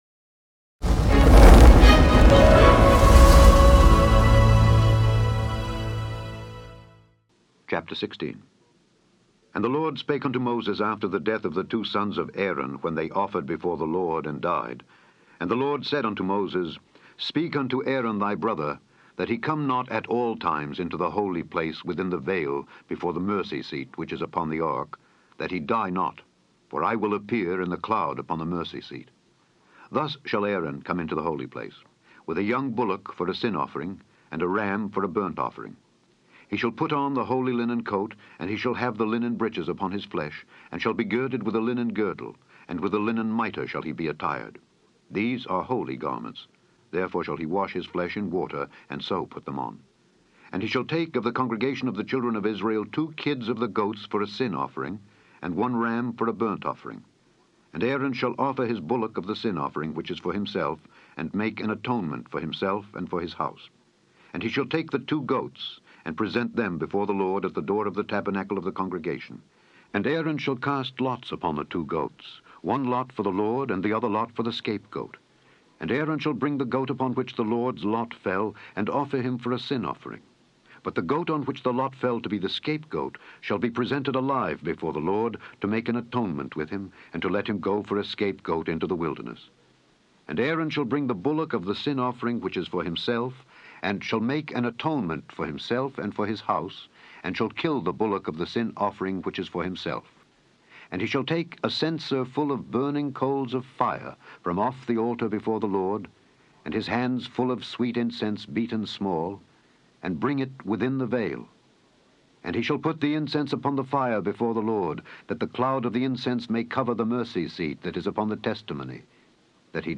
Daily Bible Reading: Leviticus 16-18
You can listen to Alexander Scourby read Leviticus 16-18 in this podcast.